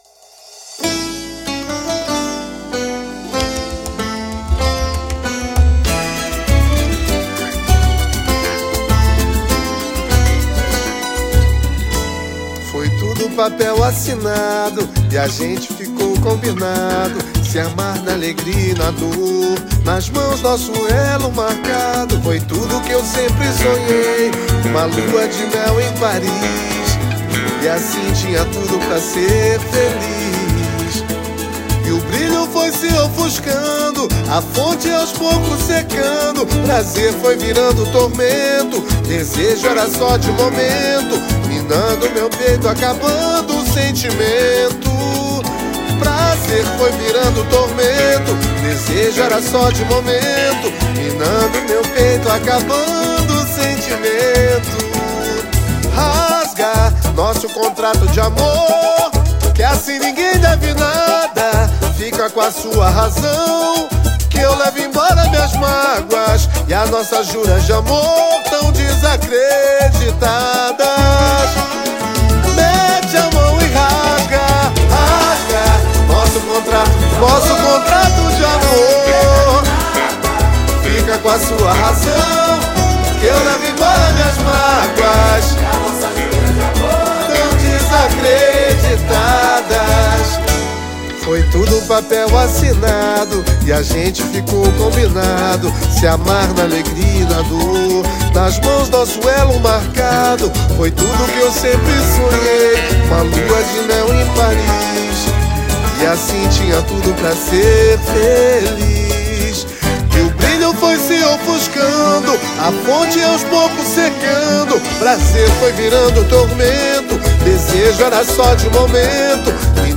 EstiloSamba